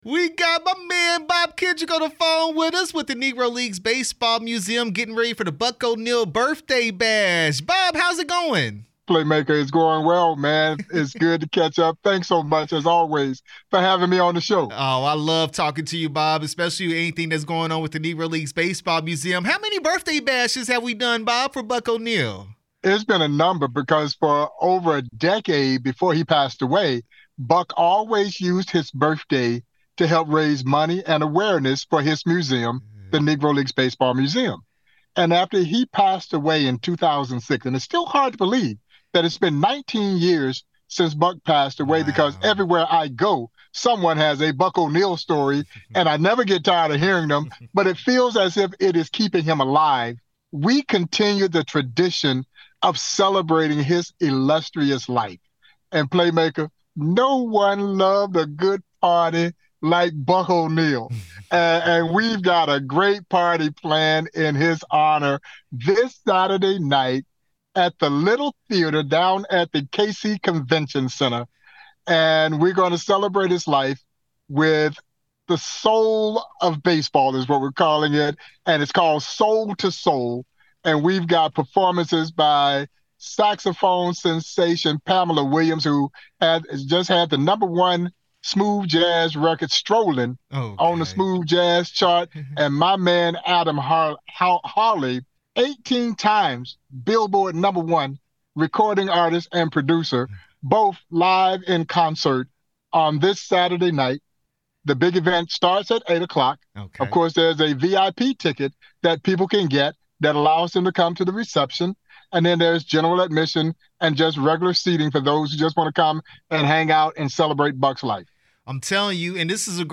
NLBM Soul 2 Soul Buck O’Neil’s 114th Birthday Celebration interview 11/12/25